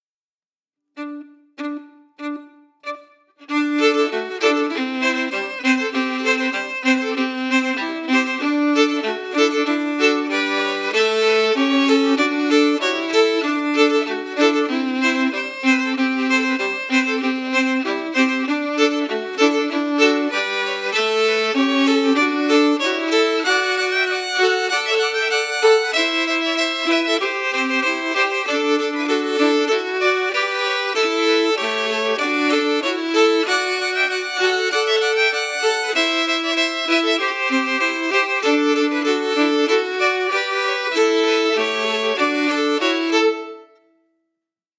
Key: D-major
Form: Reel, Song
Harmonies Emphasis
Linkumdoddie-harmonies-emphasized.mp3